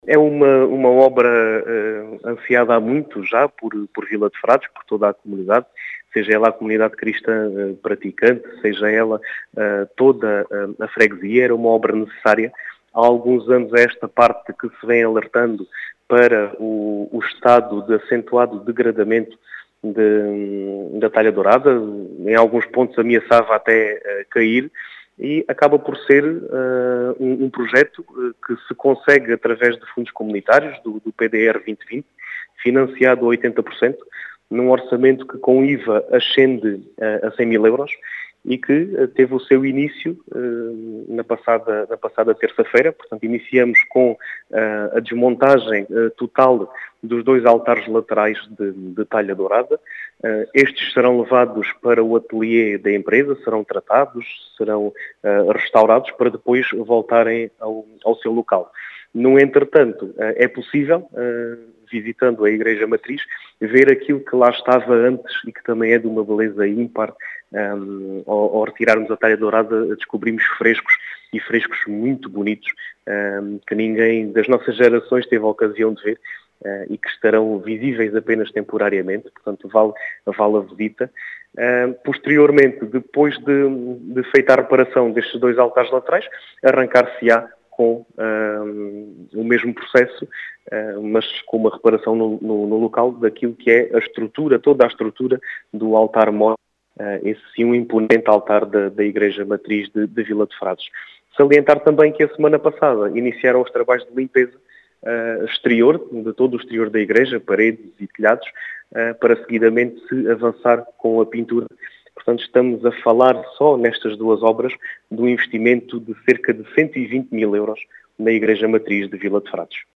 As explicações são de Diogo Conqueiro, presidente da Junta de Freguesia de Vila de Frades, que fala numa obra “ansiada e necessária”.